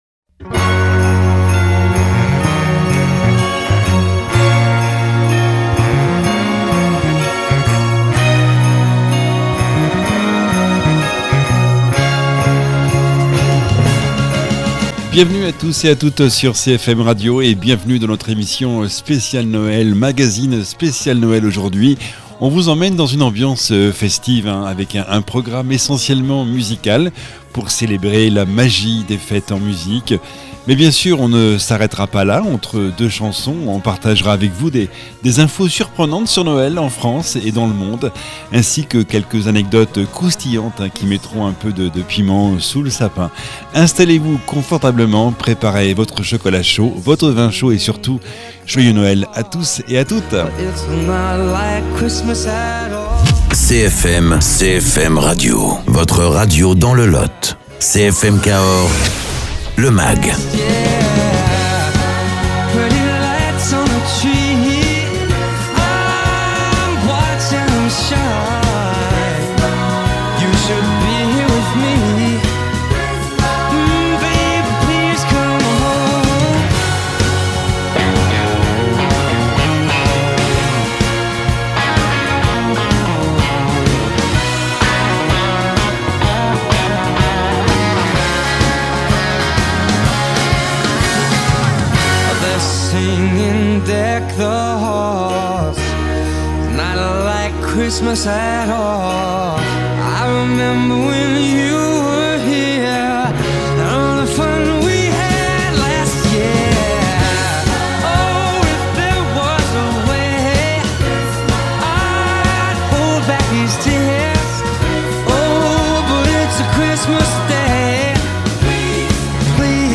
Émission spéciale Noël, principalement musicale, pour célébrer la magie des fêtes en musique. Entre deux chansons, nous vous partageons des infos surprenantes sur Noël, en France et dans le monde, ainsi que quelques anecdotes croustillantes qui viendront mettre un peu de piment sous le sapin !